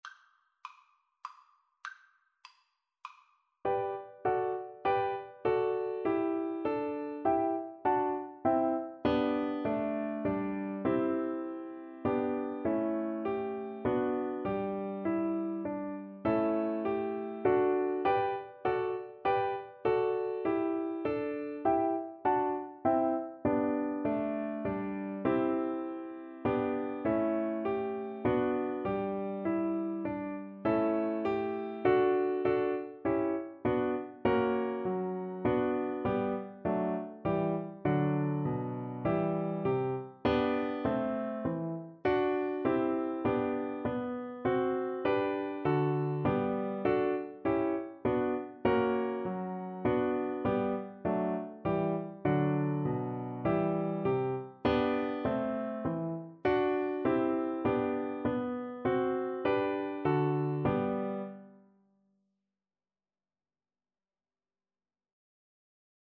Play (or use space bar on your keyboard) Pause Music Playalong - Piano Accompaniment Playalong Band Accompaniment not yet available reset tempo print settings full screen
Allegretto
3/4 (View more 3/4 Music)
G major (Sounding Pitch) (View more G major Music for Viola )
Classical (View more Classical Viola Music)